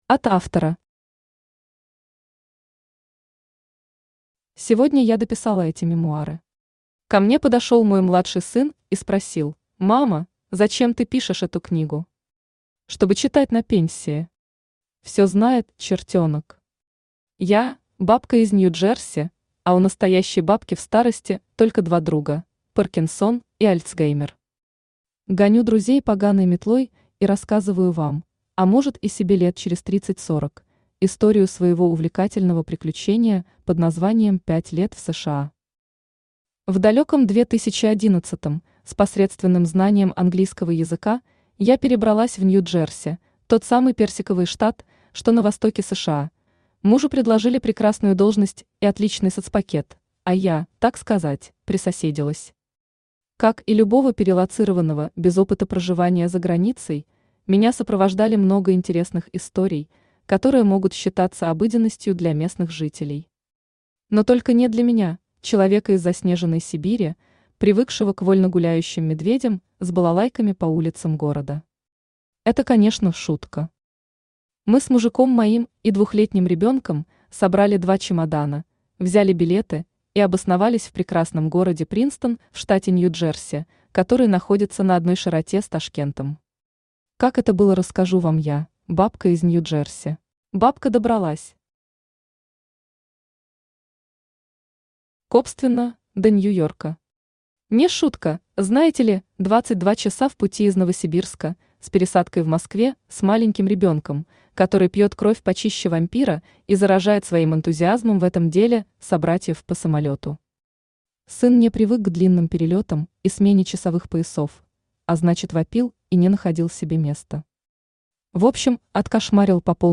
Аудиокнига Записки бабки из Нью-Джерси | Библиотека аудиокниг
Читает аудиокнигу Авточтец ЛитРес.